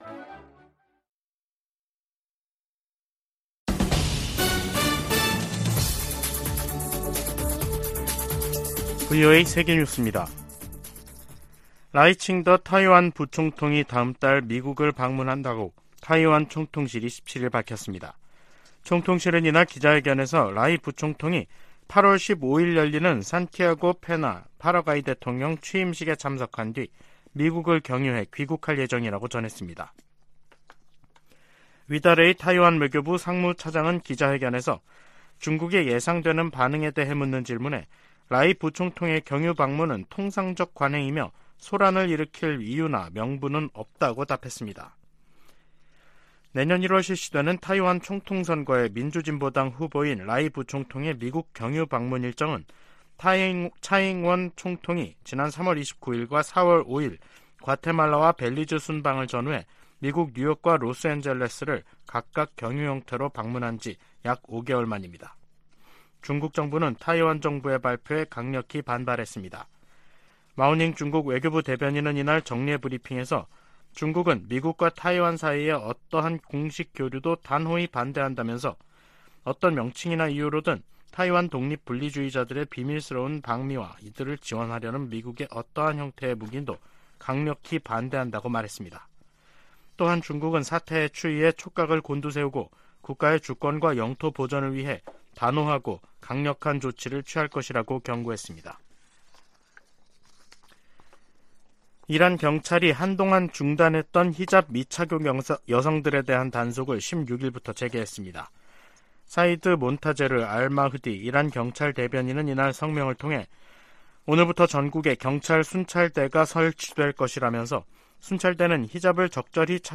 VOA 한국어 간판 뉴스 프로그램 '뉴스 투데이', 2023년 7월 17일 3부 방송입니다. 김여정 북한 노동당 부부장이 담화를 내고 대륙간탄도미사일 '화성-18형' 발사의 정당성을 주장하면서 미국을 위협했습니다. 인도네시아 자카르타에서 열린 제30차 아세안지역안보포럼(ARF) 외교장관회의에서 미한일 등 여러 나라가 북한의 탄도미사일 발사를 규탄했습니다. 미 상원에서 한국 등 동맹국의 방위비 분담 내역 의회 보고 의무화 방안이 추진되고 있습니다.